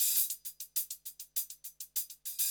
HIHAT LOP4.wav